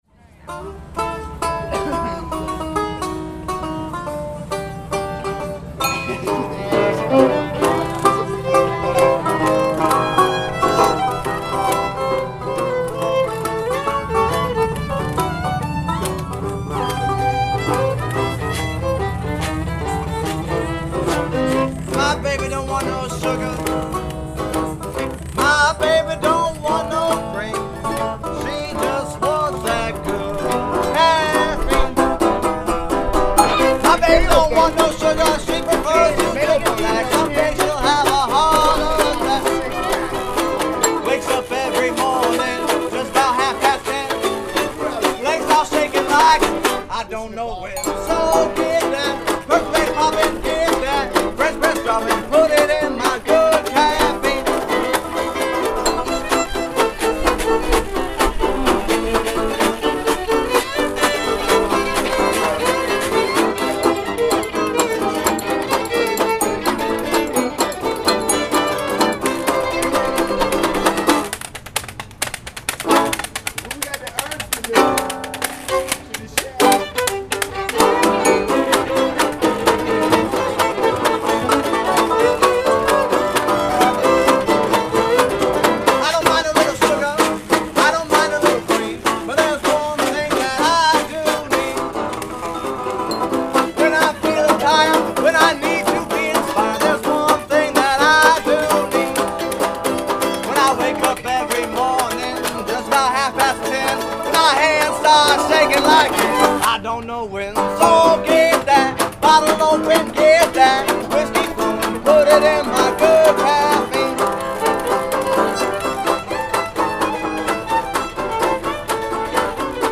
in front of the Woolworth Walk
That’s doubly true when their chosen genre is the nostalgia-driven jazz, blue and old-time hybrid that has been dominating the regional busking scene for the past several years.
The fiddle in his hand was obeying his every command, and the band was his.
It’s also worth noting that the annoying laughter you hear off to the side, and the cautiously bemused glances the band keep making off to the left, are because of some guy who is either very deep into some psychoactive drug, or very much off his meds, having an incoherent conversation with himself.